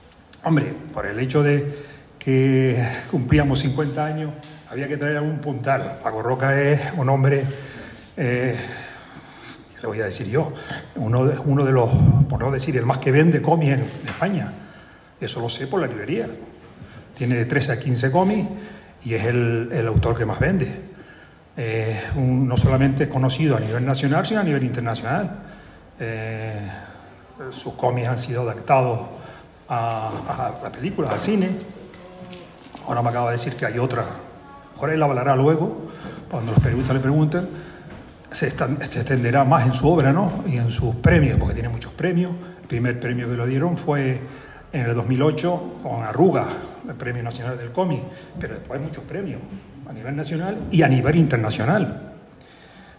Intervención